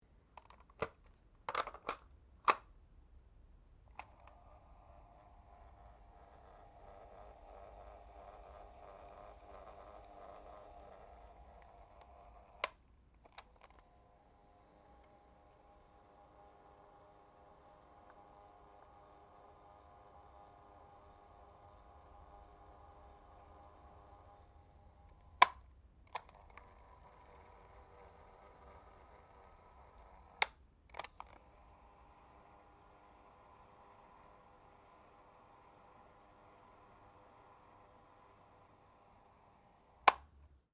Sony WM-EX 116 personal stereo